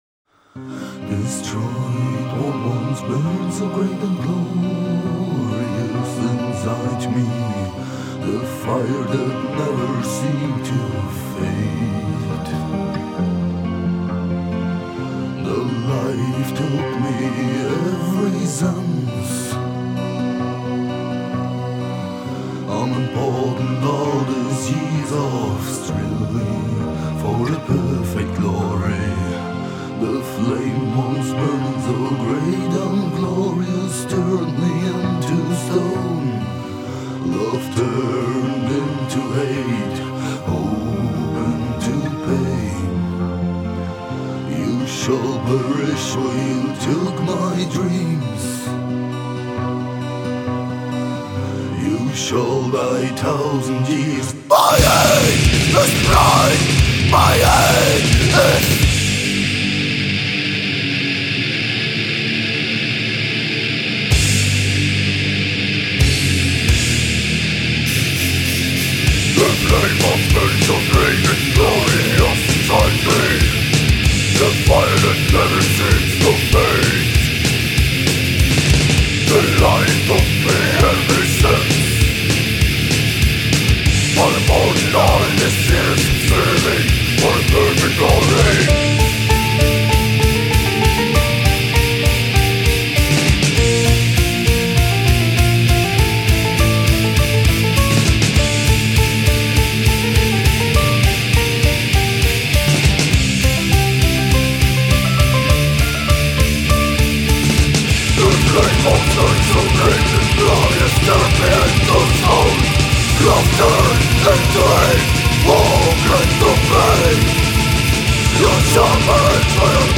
Death Metal